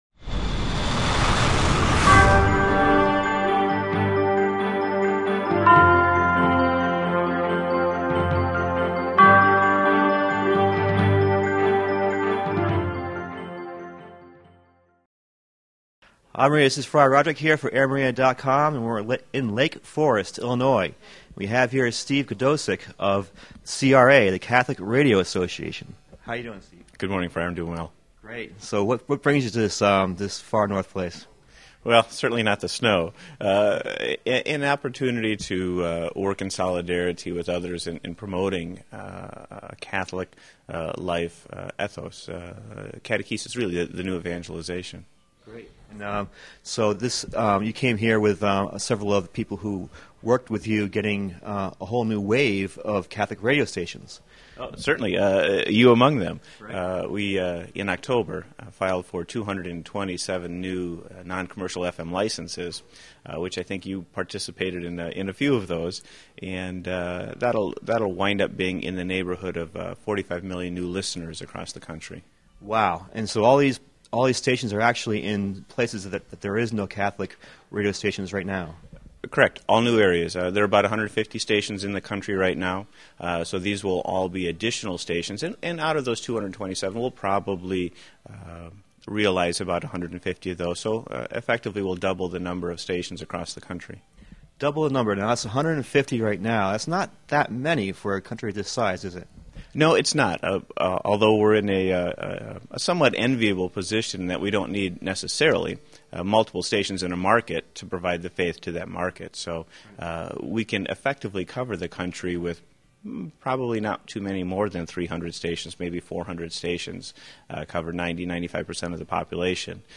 Roving Reporter #33 - Answering the call for a New Evangelization ( 6min) >>> Play Ave Maria! The Roving Reporter interviews